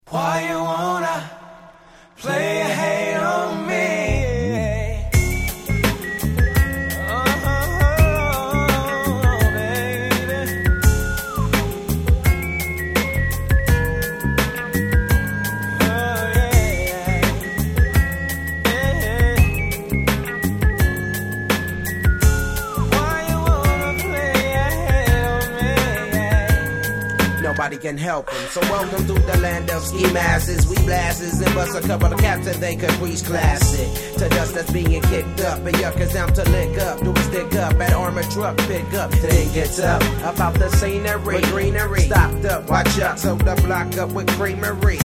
West Coast Hip Hop Classic !!